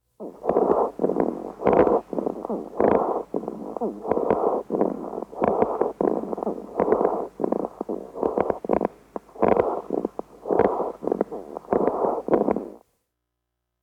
Crackles (also known as rales) are sharp crackling inspiratory sounds.
Crackles voice clip
infant-mild-insipratory-and-expiratory-crackles.wav